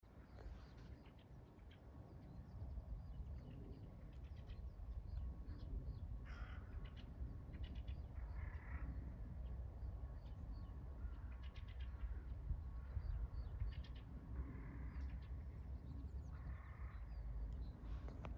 Fieldfare, Turdus pilaris
StatusVoice, calls heard
NotesDzirdams no upes aizaugušās dałas. Starp vārnu un zīlītes balsīm dzirdama čirkstinoša dziedāšana.